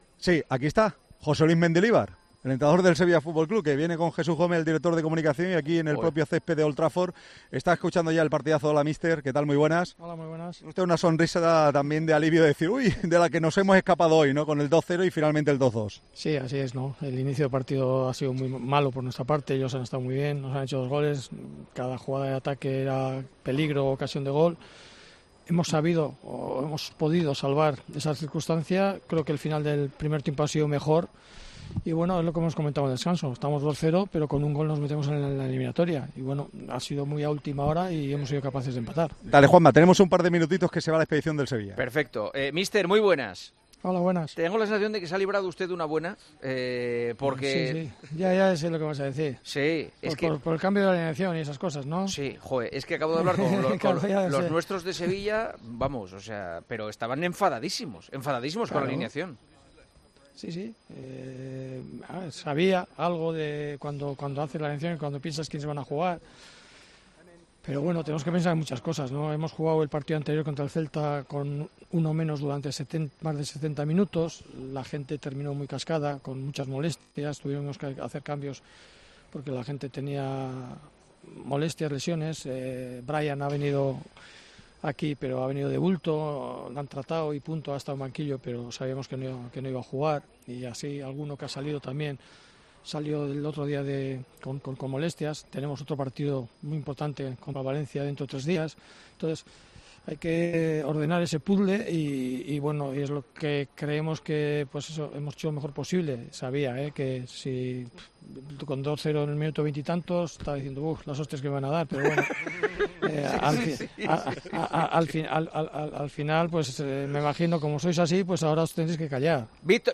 El entrenador del Sevilla analizó en El Partidazo de COPE el empate de los andaluces ante el Manchester United con dos goles en los últimos minutos.